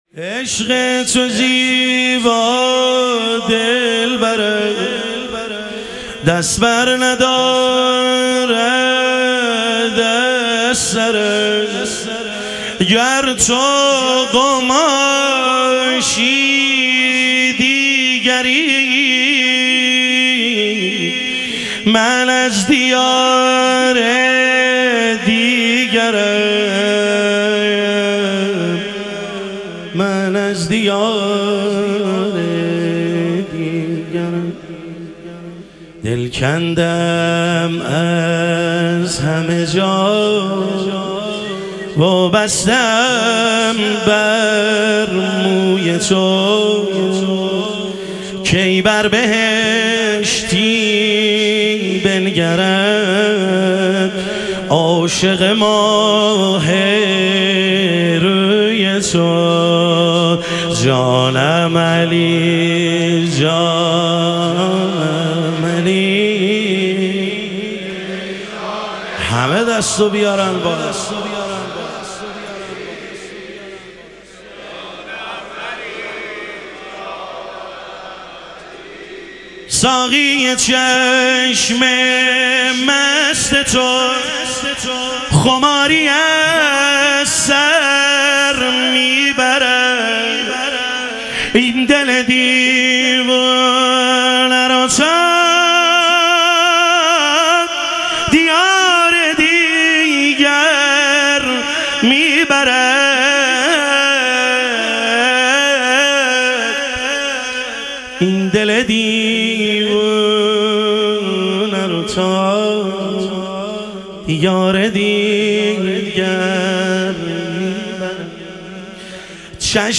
ظهور وجود مقدس حضرت امیرالمومنین علیه السلام - مدح و رجز